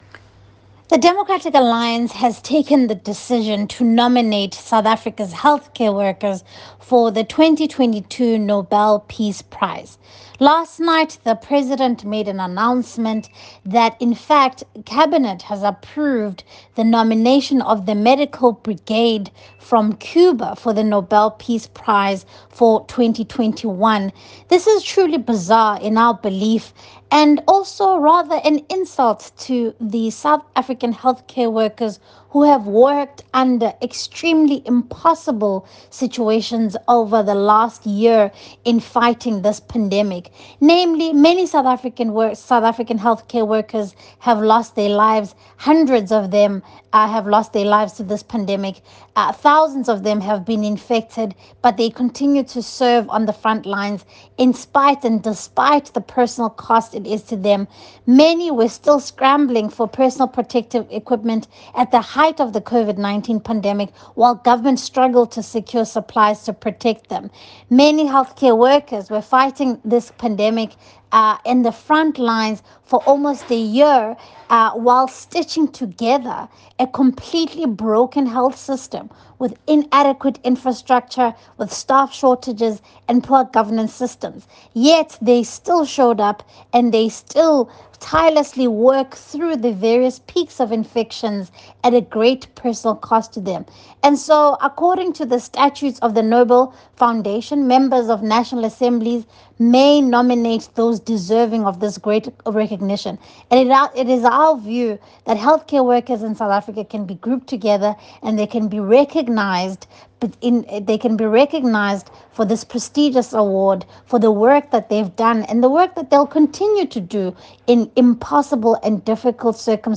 soundbite by Siviwe Gwarube MP.